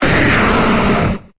Cri de Tyranocif dans Pokémon Épée et Bouclier.